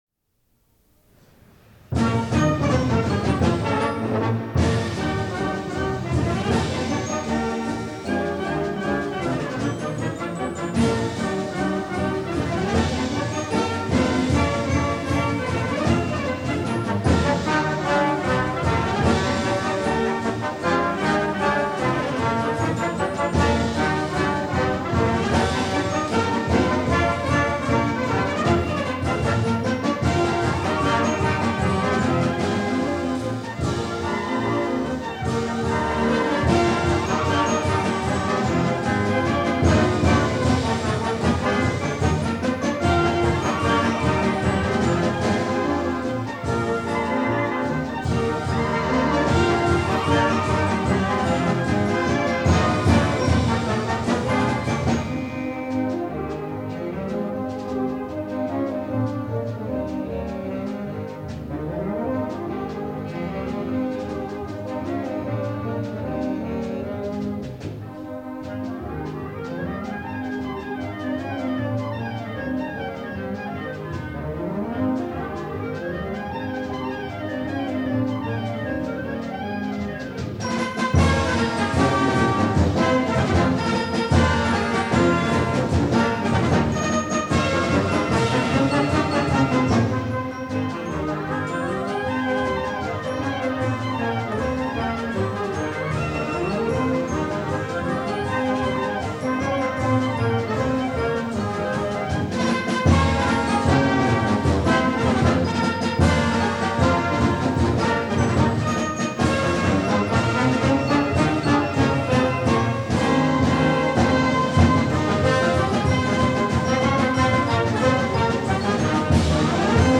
Band → Concert Marches
Voicing: Concert March